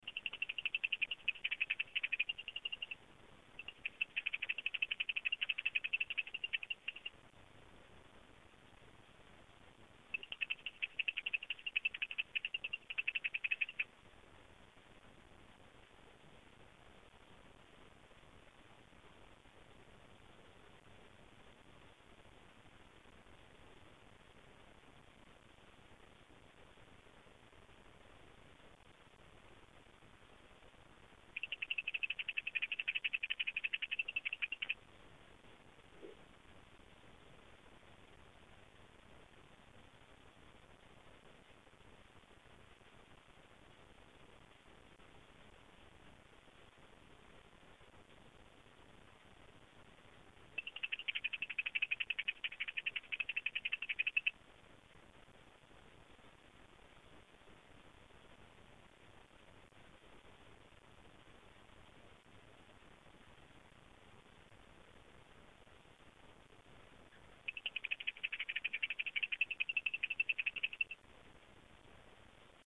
j'ai un chant de vittatus vraiment particulier qui resemble au "female near" ici...
edit: niveau intensité, c'est assez "faiblard"
je pense pas que ce soit un chantde jeune mâle, car ça fait plusieurs semaines/mois que ça dure ces chants bizarres
chantvittatus.mp3